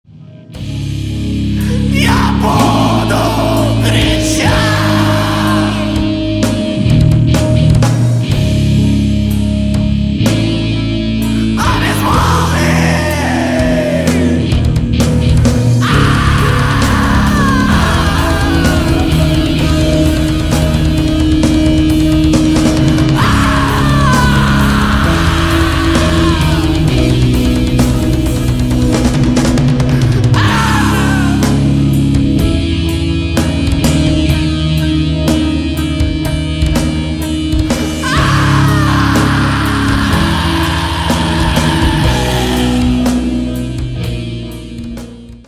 black metal
Depressive Metal
Suicidal Metal